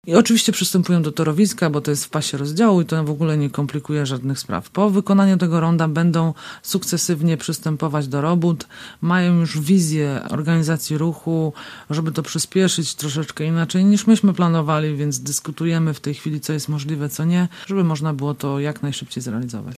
Firma zacznie prace od tymczasowego ronda przy Dobrej. D dopóki rondo nie powstanie, żaden odcinek Kostrzyńskiej nie zostanie zamknięty- mówiła dziś w naszym studiu wiceprezydent Agnieszka Surmacz: